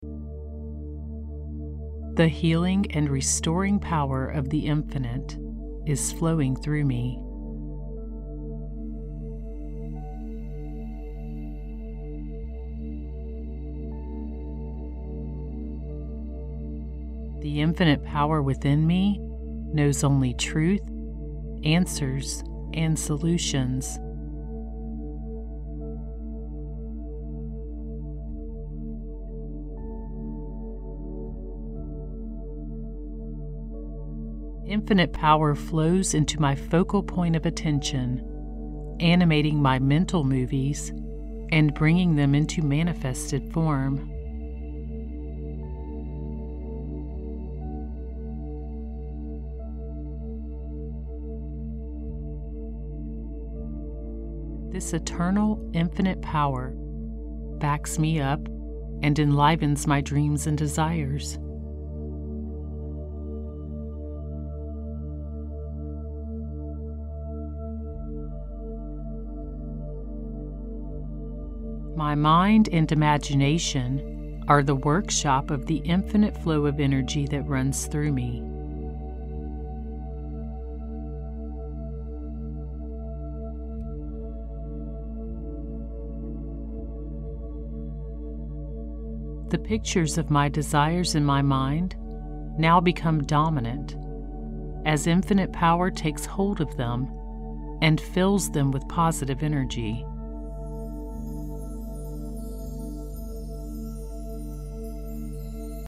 This 1-Hour music track is tuned to 528Hz to promote a confident state and assist you in manifesting miracles in your life!